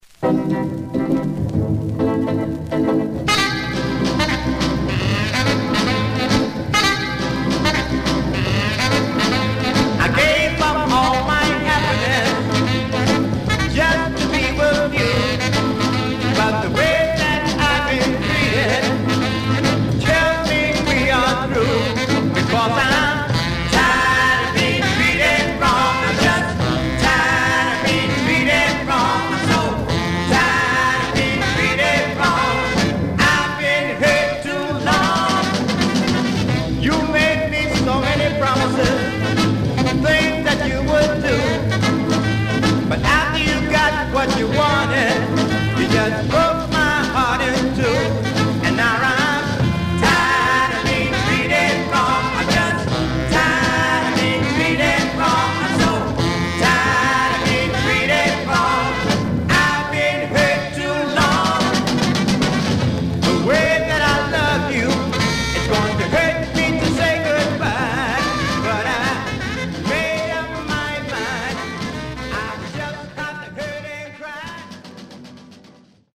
Surface noise/wear Stereo/mono Mono
Soul